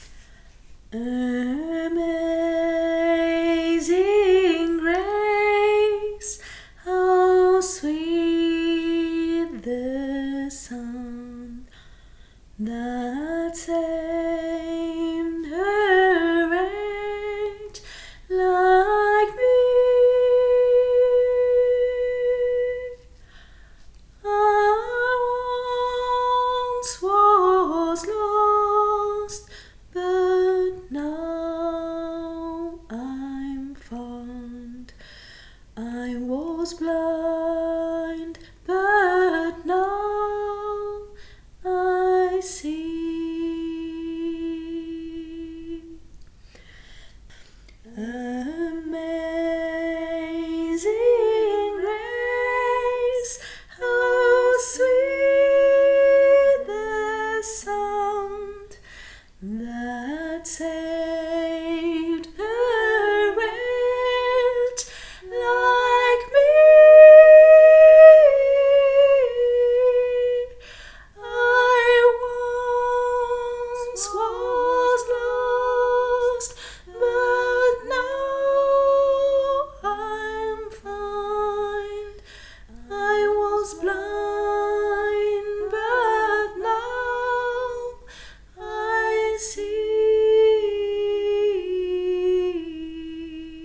CHOEUR EPEHEMERE 2024
Amazing grace contre chant
amazing-grace-contre-chant.mp3